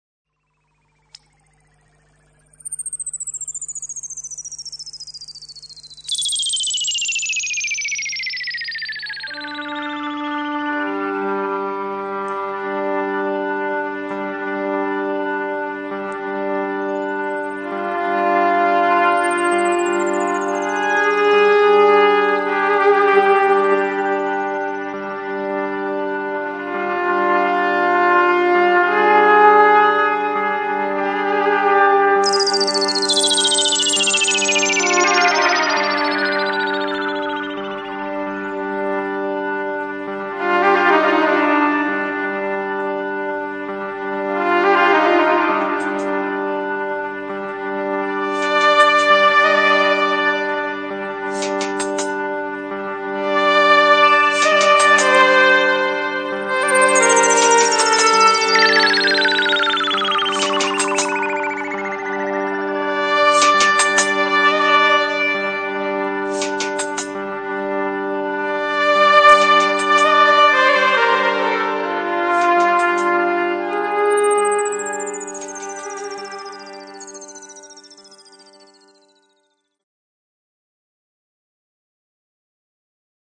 Maraton soudobé hudby 2004